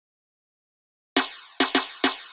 103 BPM Beat Loops Download